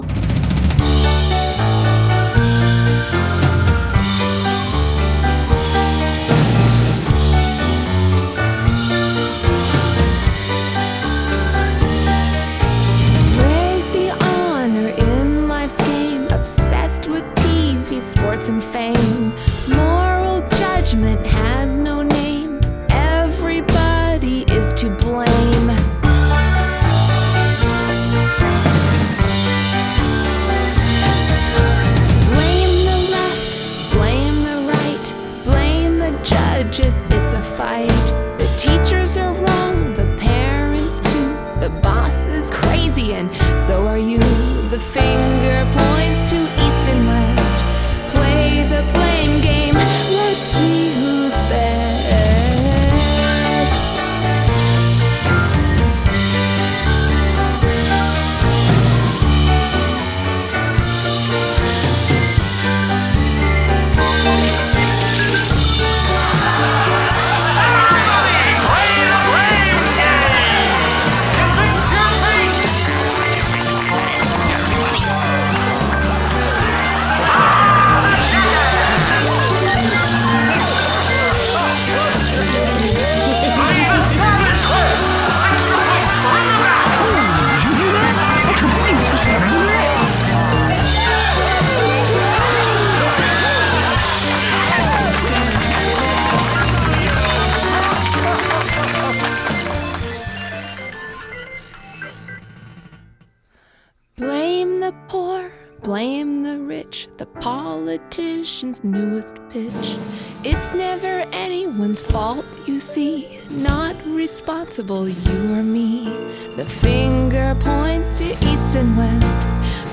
Adult Contemporary
Indie Pop , Musical Theatre